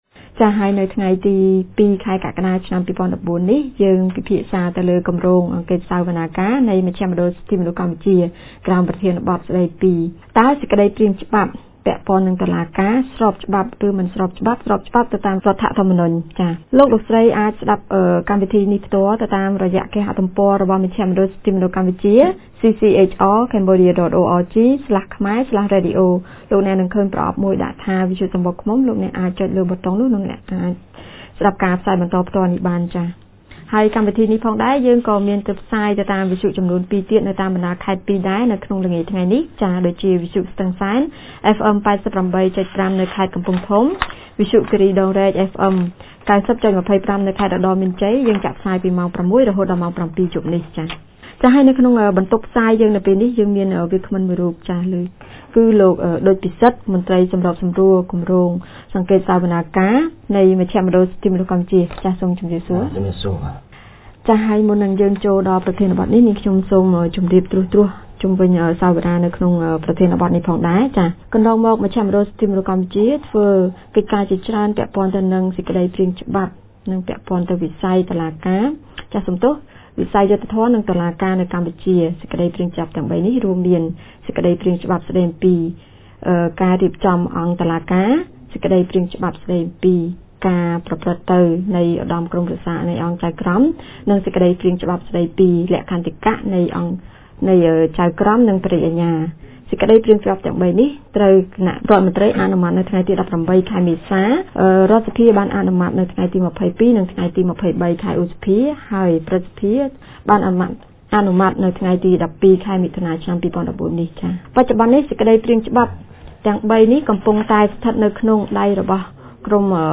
On 02 July 2014, CCHR TMP held a radio talk discussion on the unconstitutionality of the three judicial draft laws.